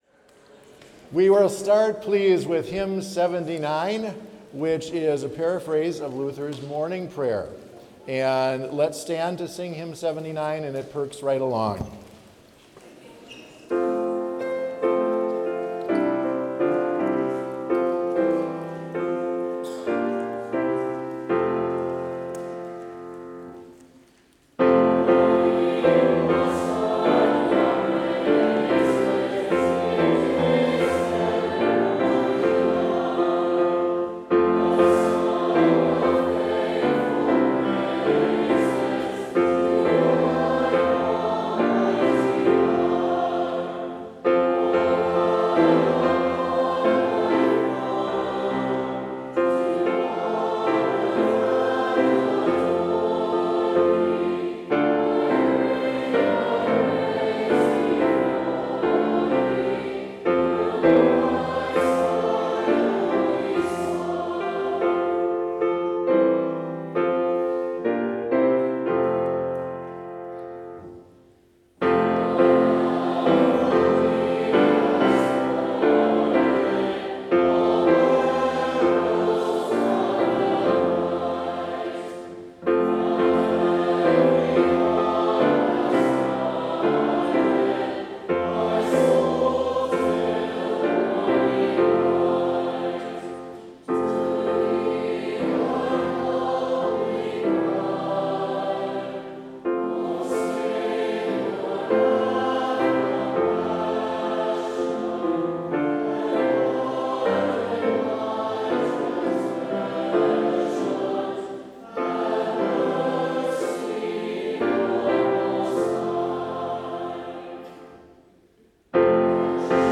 Complete service audio for Chapel - Thursday, October 2, 2025
Hymn 72 - Thy Strong Word Did Cleave the Darkness